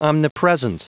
Prononciation du mot omnipresent en anglais (fichier audio)
Prononciation du mot : omnipresent